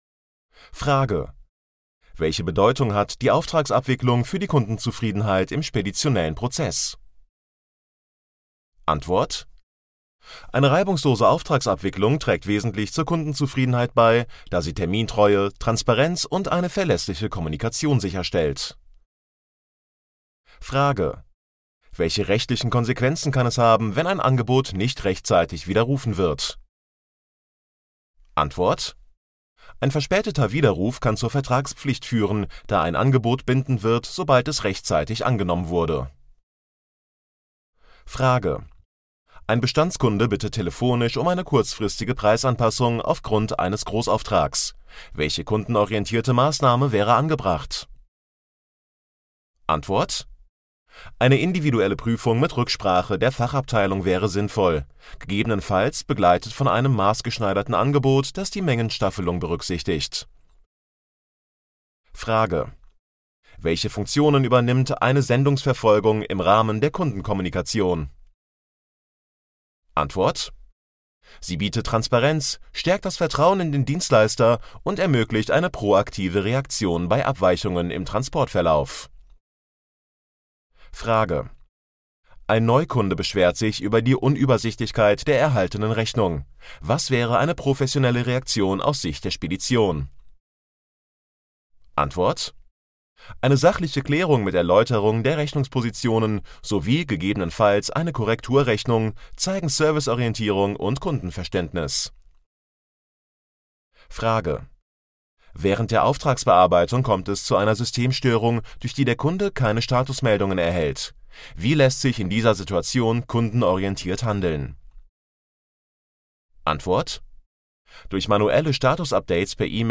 MP3 Hörbuch Kaufmann für Spedition und Logistikdienstleistung - Download